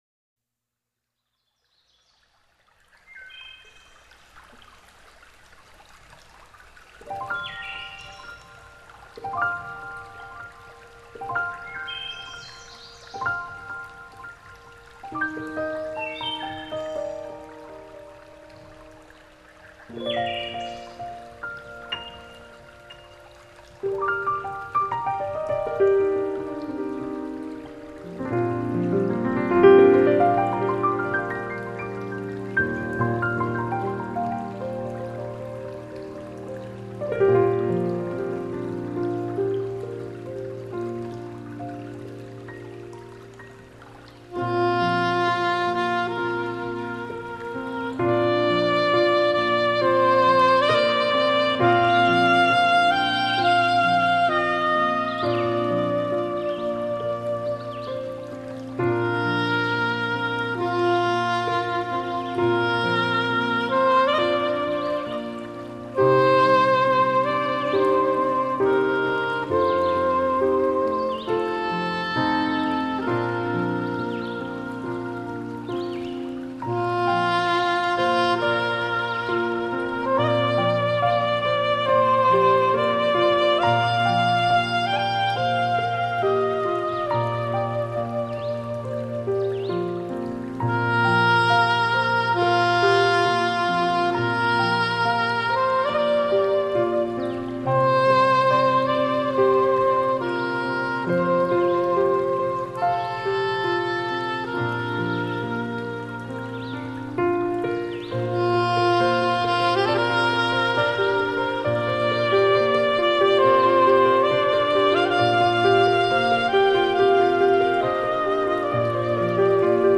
浪漫新世纪音乐
PIANO V.S SAXPHONE
再加上各式各樣的大自然聲音，使您在聆聽時恍若置身世外桃源。